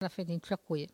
Il a fait un piston Cette Locution a pour titre "Il a fait un piston". Elle provient de Saint-Jean-de-Monts.